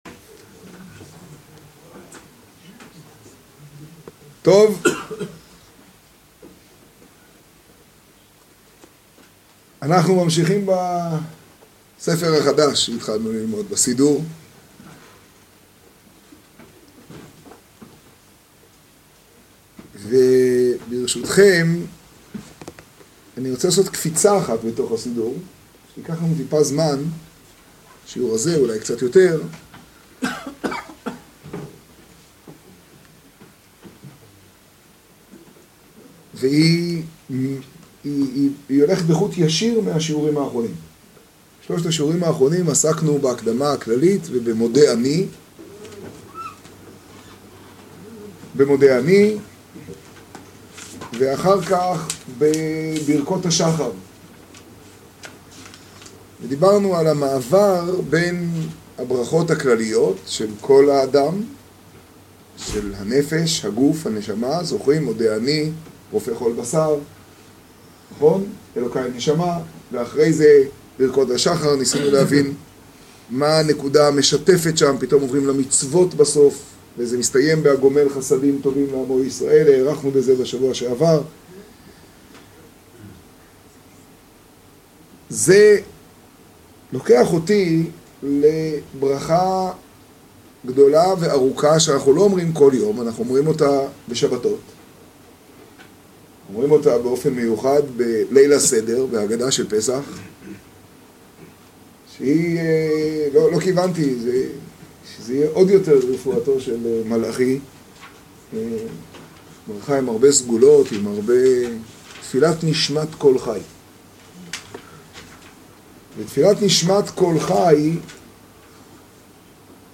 השיעור בחצור, תשעד.